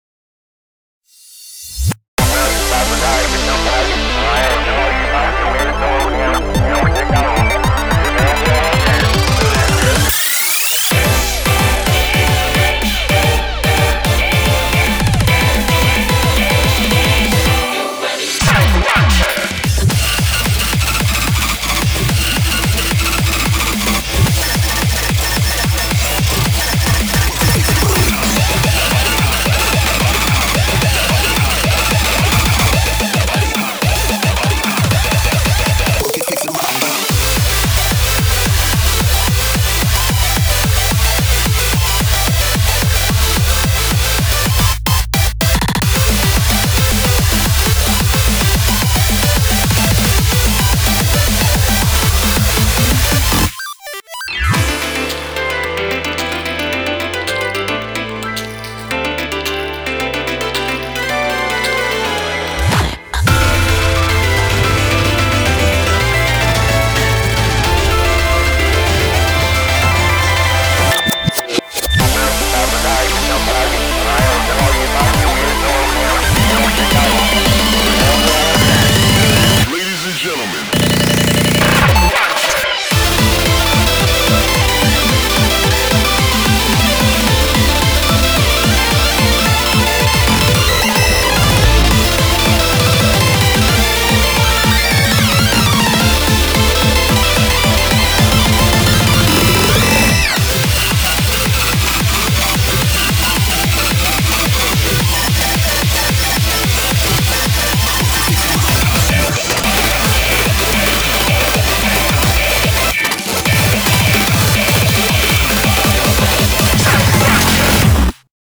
BPM110-440
Audio QualityPerfect (High Quality)
NOTE: Core BPM is 220.